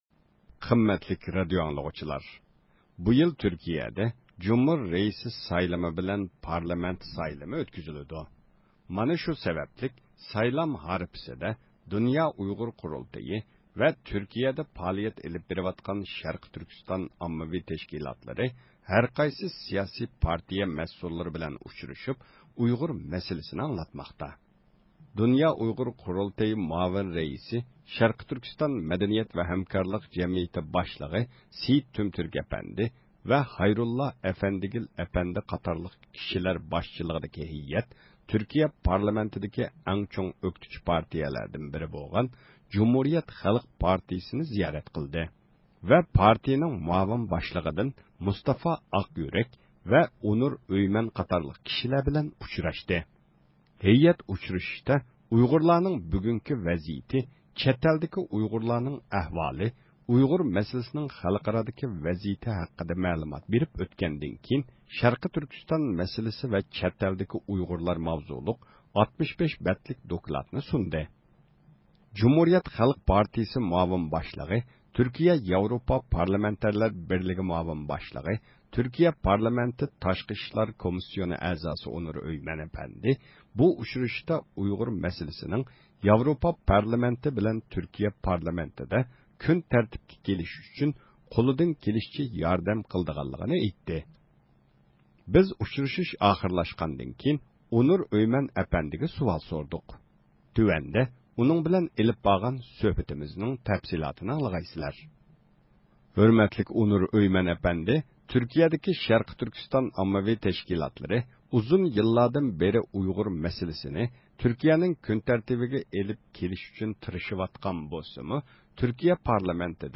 بىز ئۇچرىشىش ئاخىرلاشقاندىن كېيىن ئونۇر ئۆيمەن ئەپەندىگە سۇئال سورىدۇق.